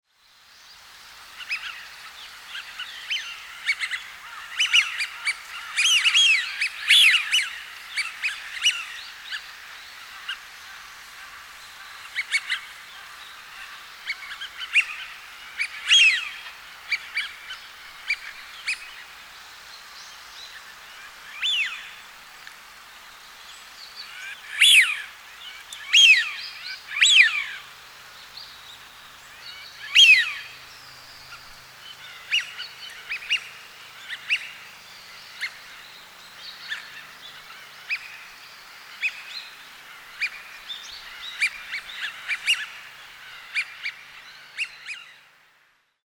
hidorigamo_c1.mp3